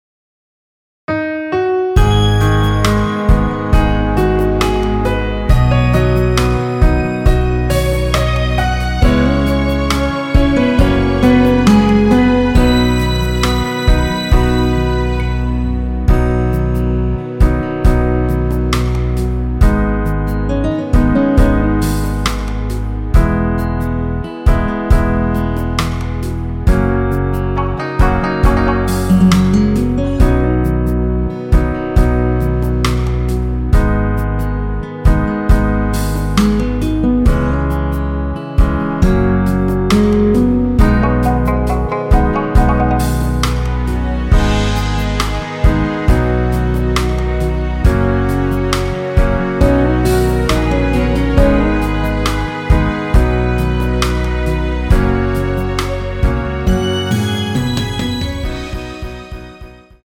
원키에서(-4)내린 MR입니다.
앞부분30초, 뒷부분30초씩 편집해서 올려 드리고 있습니다.